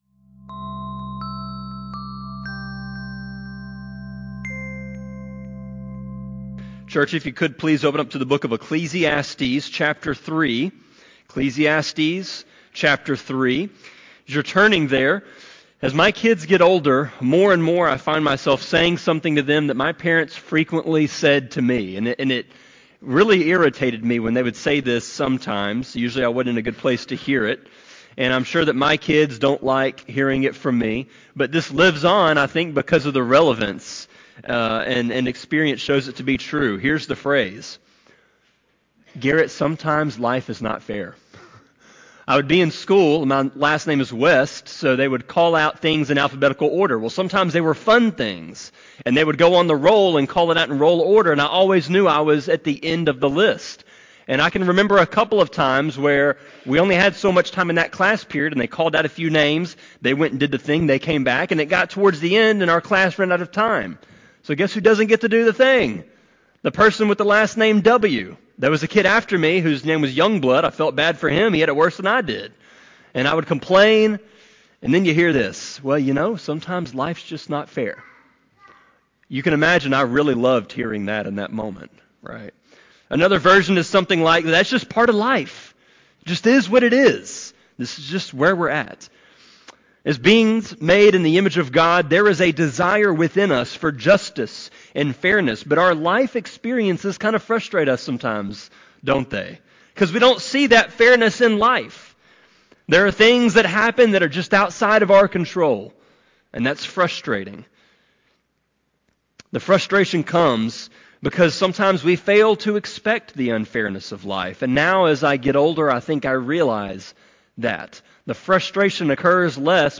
Sermon-25.2.9-CD.mp3